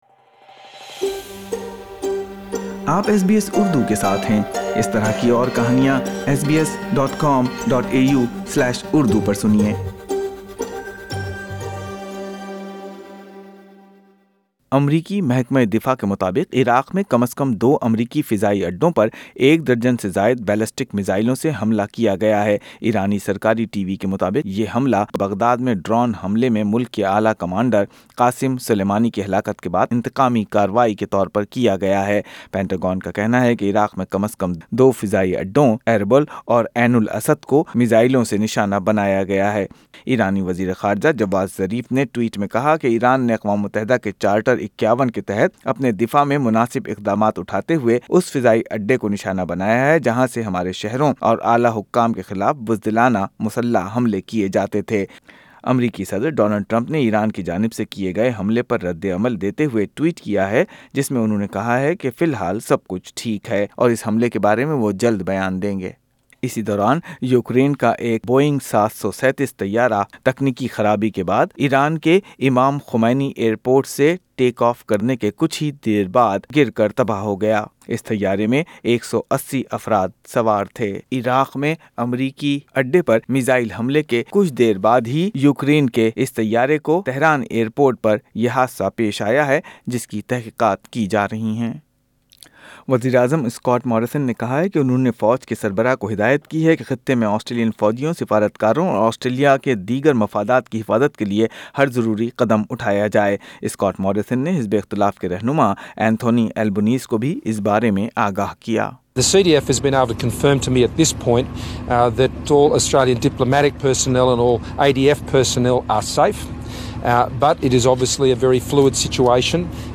ایس بی ایس اردو خبریں نو جنوری ۲۰۲۰